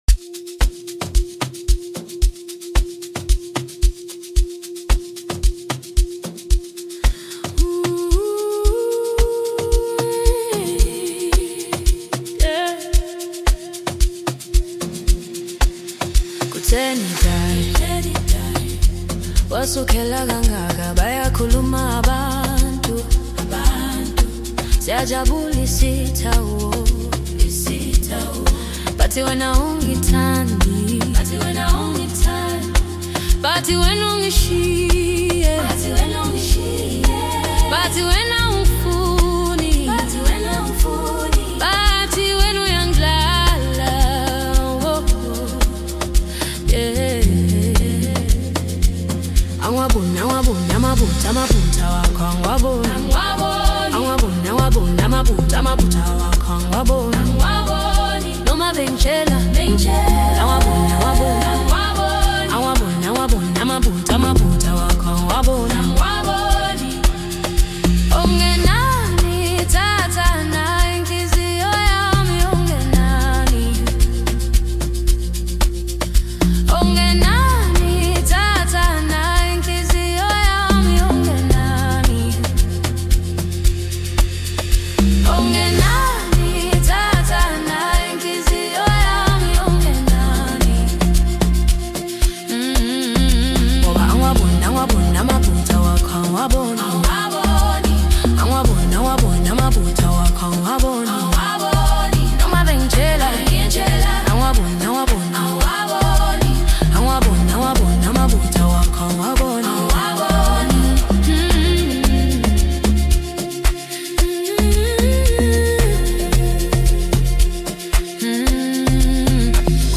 Home » Amapiano » Maskandi